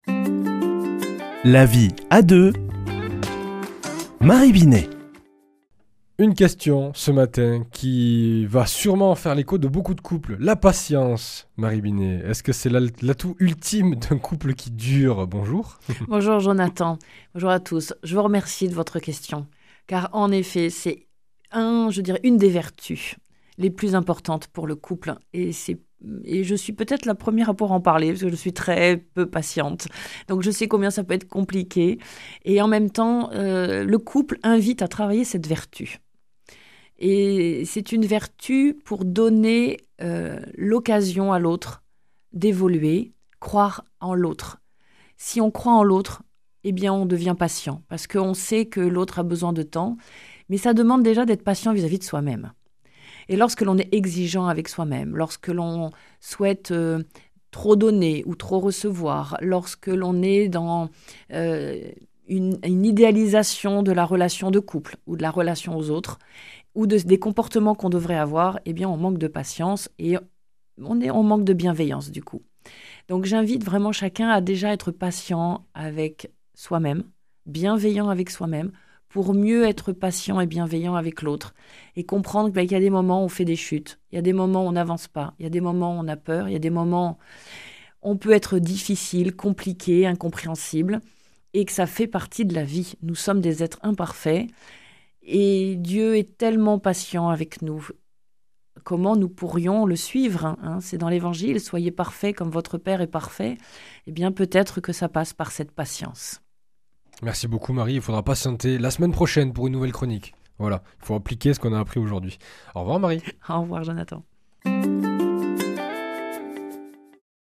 mardi 15 octobre 2024 Chronique La vie à deux Durée 4 min
Une émission présentée par